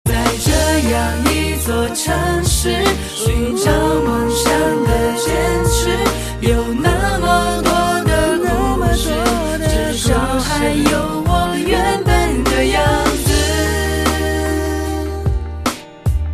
M4R铃声, MP3铃声, 华语歌曲 61 首发日期：2018-05-15 10:11 星期二